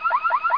Soundscape: Paranal software sounds
There are three distinctive sounds at the Paranal Control Room during a night of observations: conversations between astronomers and telescope operators, chairs rolling from one desk to another to check different stations, and the sound of the instruments’ software—something very distinctive at Paranal and not very common on other observatories.
The software of each instrument on Paranal plays certain sounds when there is some action required by the operators, or something goes wrong, or something was successful.
ss-paranal-software-car-alarm_mono.mp3